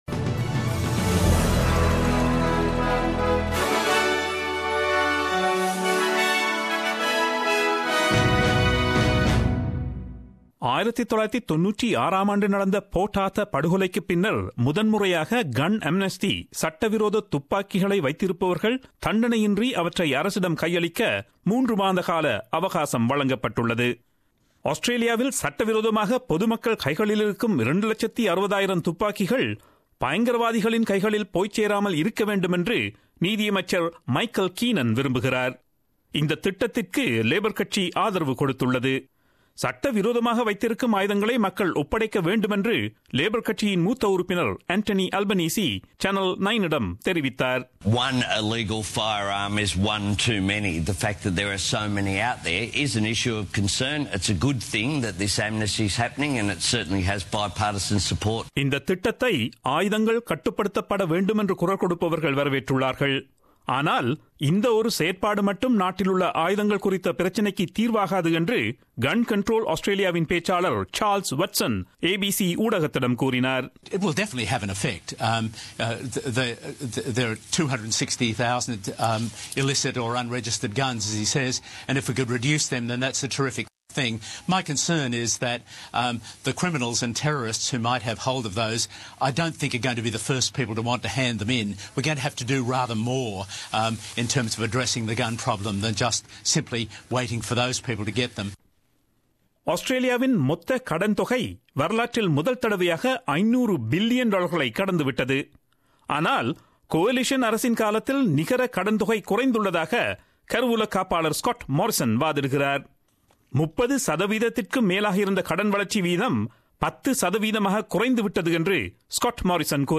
Australian news bulletin aired on Friday 16 June 2017 at 8pm.